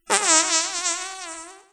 fart5
fart fun funny sound effect free sound royalty free Funny